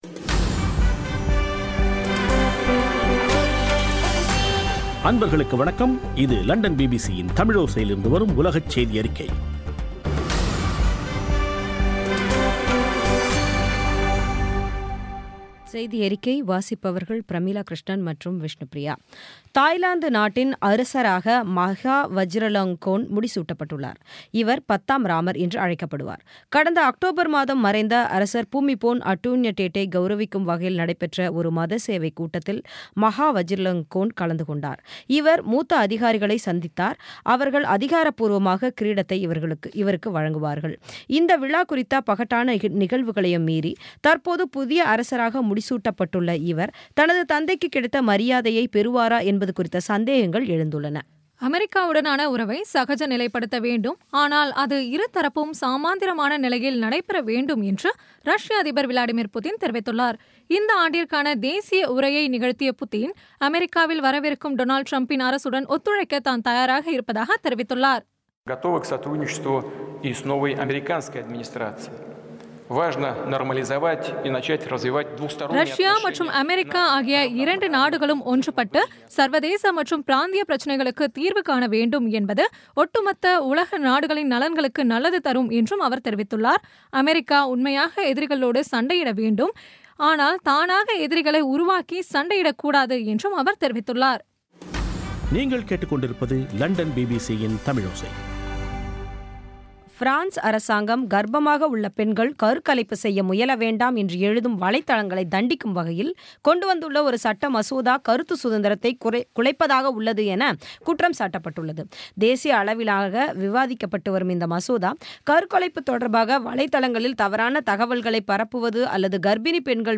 பி பி சி தமிழோசை செய்தியறிக்கை (1/12/16)